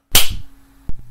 Baldi Ruler Slap